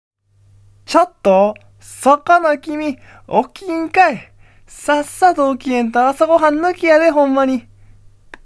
関西弁音源は